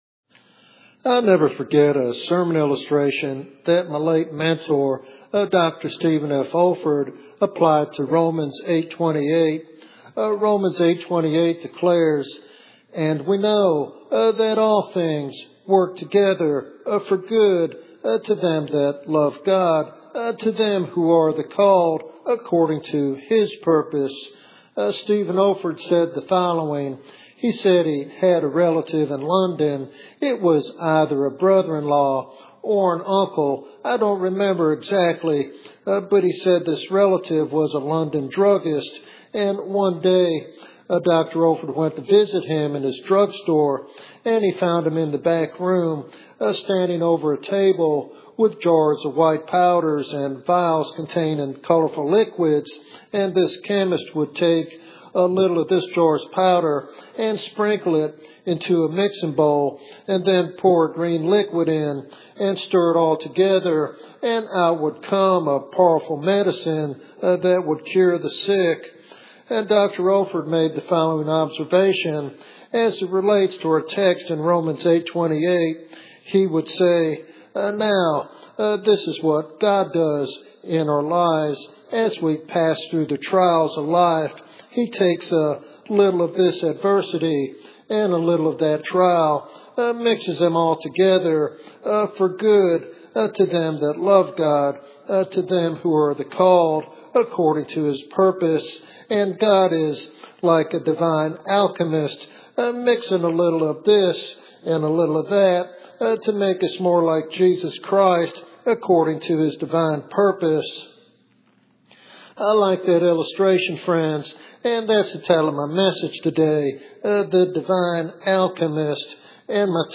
This devotional sermon offers hope and assurance that God is actively working for good in every circumstance.